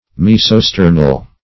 Mesosternal \Mes`o*ster"nal\